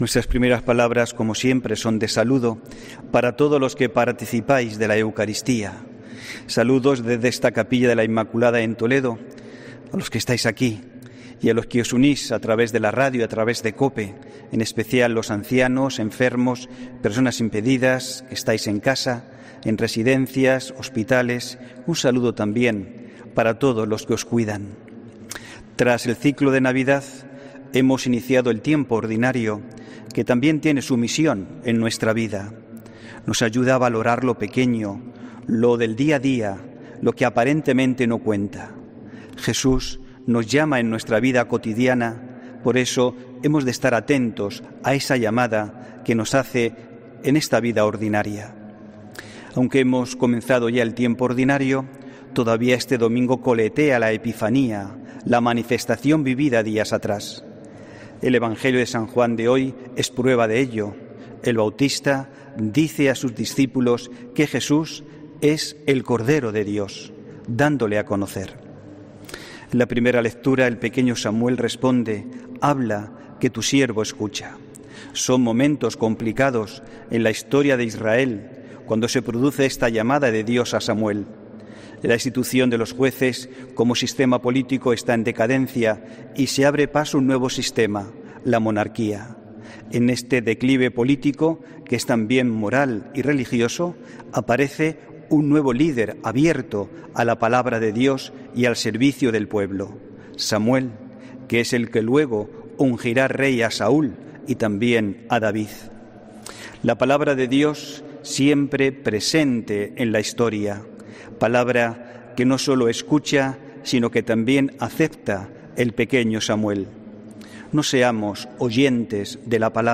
HOMILÍA 17 ENERO 2021